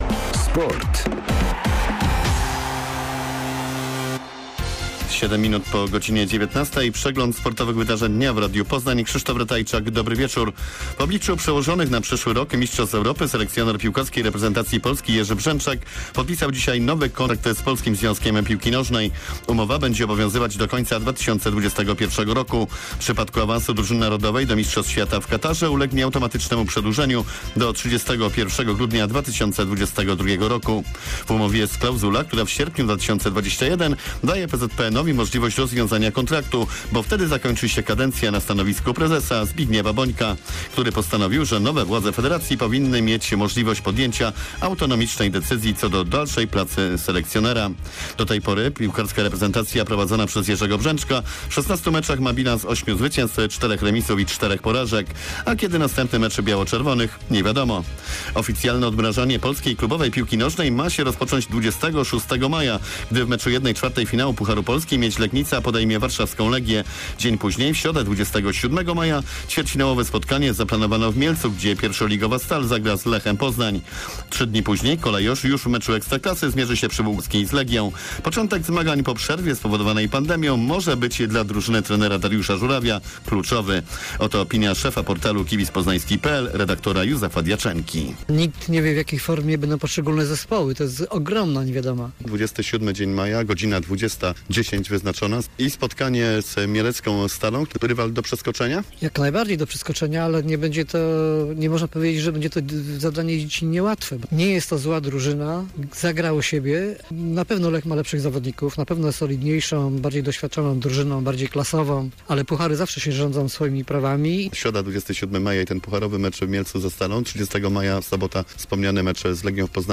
Serwis sportowy z dn. 18 maja 2020.